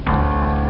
Chord Inst Sound Effect
chord-inst.mp3